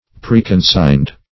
Search Result for " preconsigned" : The Collaborative International Dictionary of English v.0.48: Preconsign \Pre`con*sign"\, v. t. [imp.
preconsigned.mp3